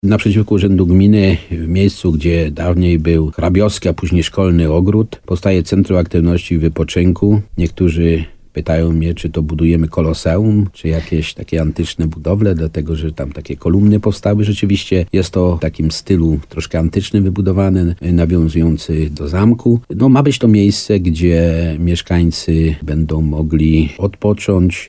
Ma być to miejsce, gdzie mieszkańcy będą mogli odpoczywać – mówi wójt Nawojowej Stanisław Kiełbasa, który zapowiada, że ma to być oaza spokoju.